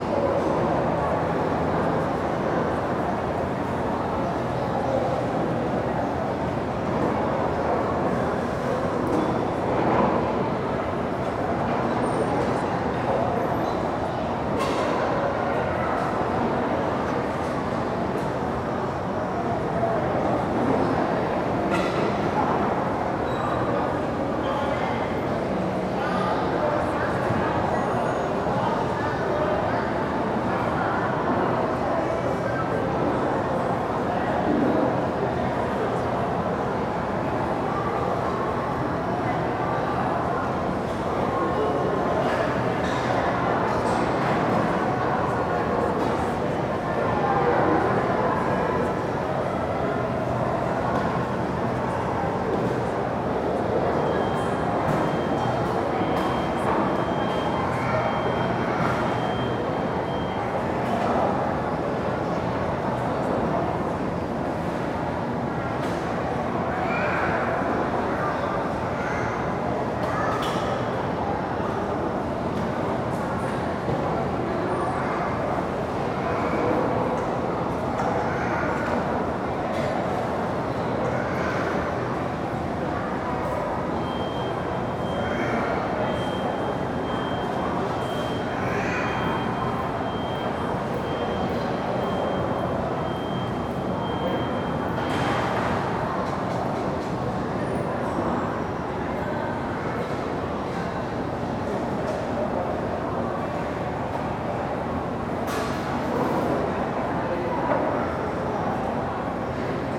CSC-04-249-LE - Ambiencia araguaia shopping praca de alimentacao afastado muitas pessoas, utensilios.wav